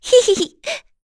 Dosarta-Vox_Skill1-3.wav